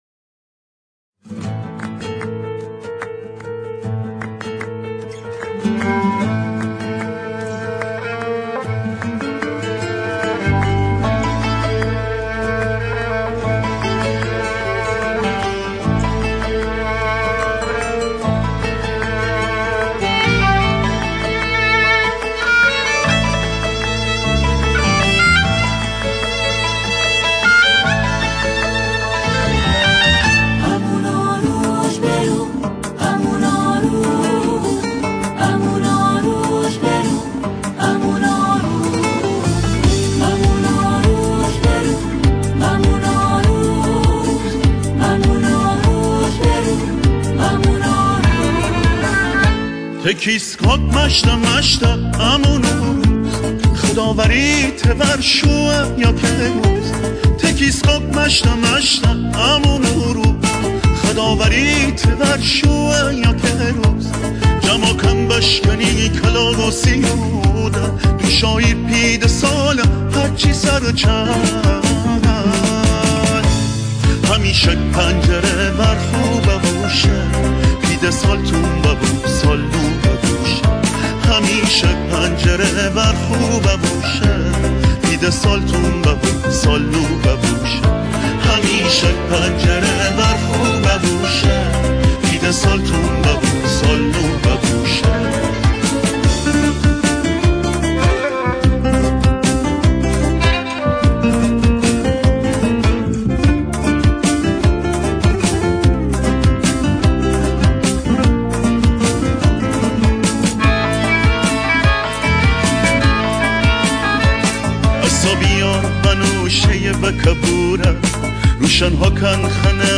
آنها در این قطعه، شعری را با گویش مازندرانی همخوانی می‌کنند.